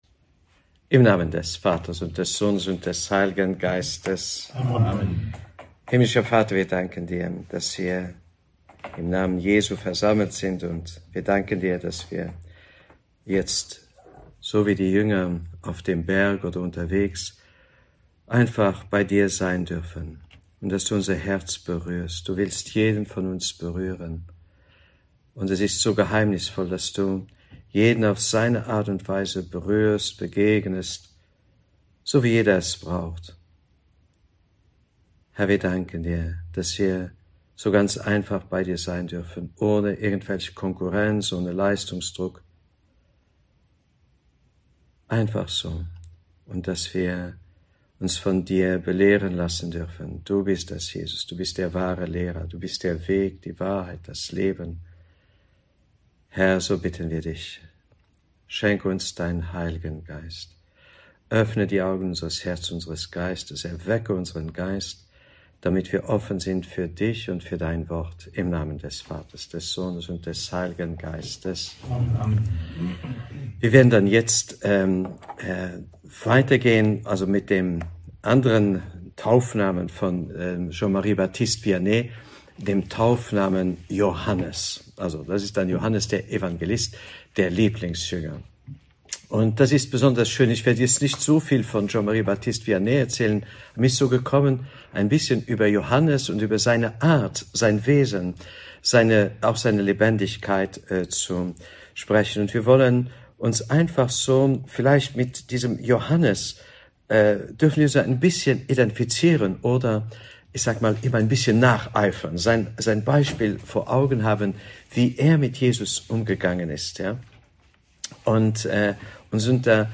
Katholische Predigten & Vorträge Podcast